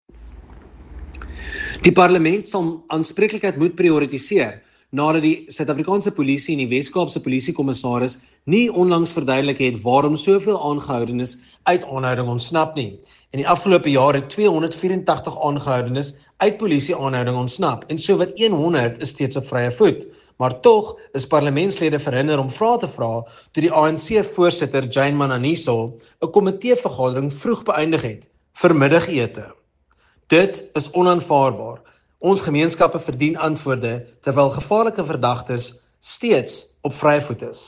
Issued by Nicholas Gotsell MP – DA NCOP Member on Security & Justice